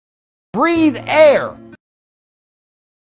andrew-tate-breath-air.wav